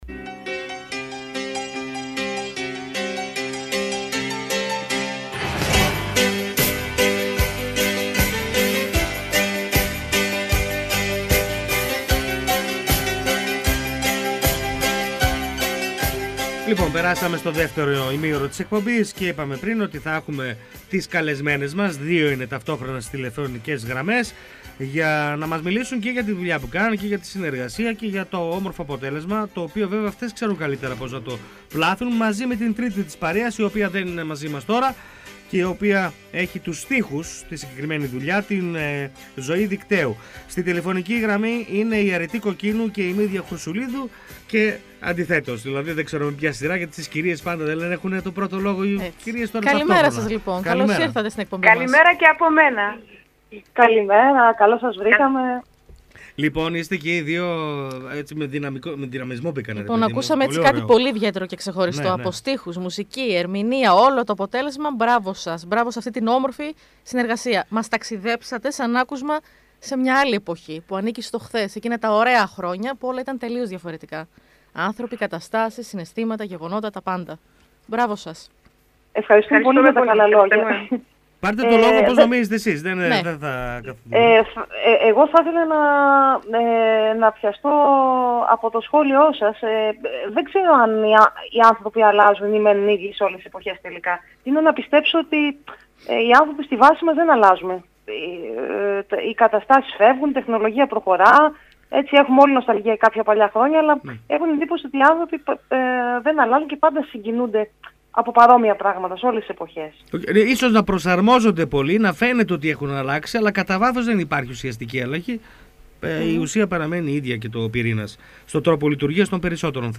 «Μέρα μεσημέρι» Καθημερινό ραδιοφωνικό μαγκαζίνο που ασχολείται με ρεπορτάζ της καθημερινότητας, παρουσιάσεις νέων δισκογραφικών δουλειών, συνεντεύξεις καλλιτεχνών και ανάδειξη νέων ανθρώπων της τέχνης και του πολιτισμού.